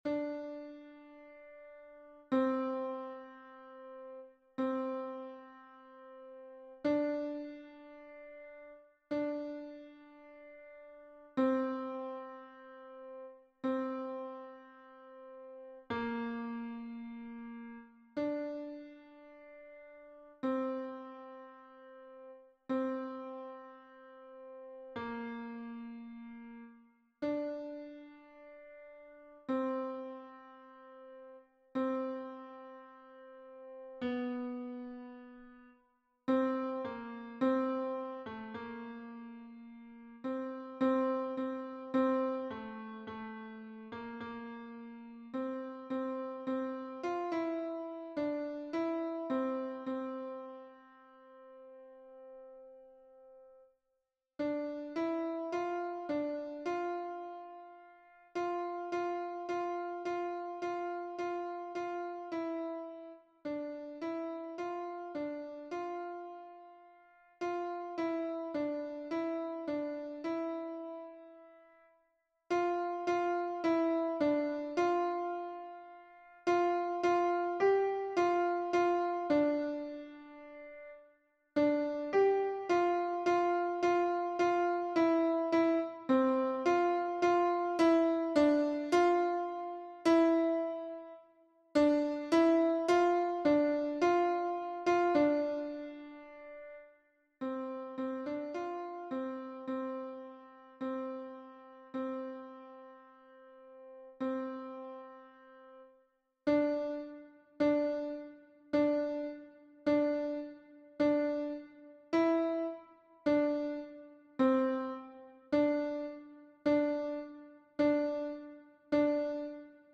MP3 version piano
Alto 1 (en haut)